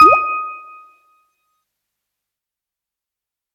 Notification Bell and Water
bell bells bubble bubbles ding dong notification water sound effect free sound royalty free Sound Effects